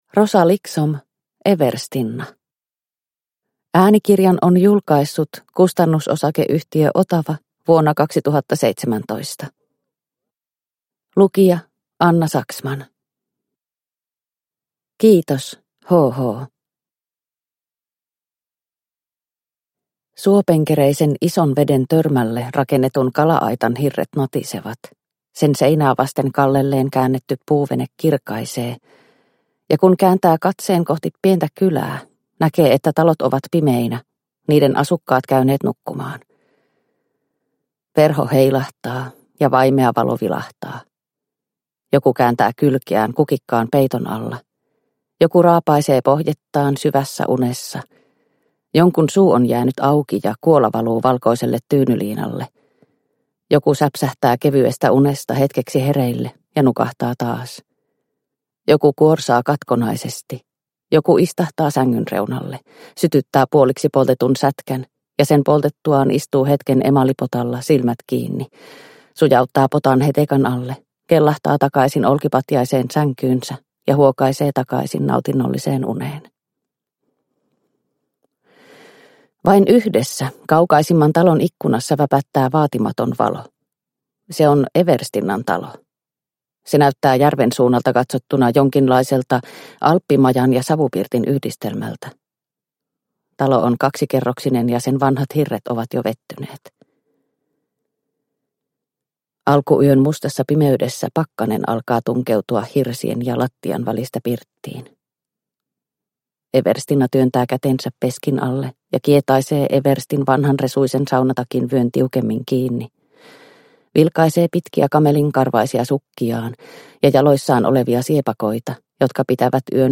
Everstinna – Ljudbok – Laddas ner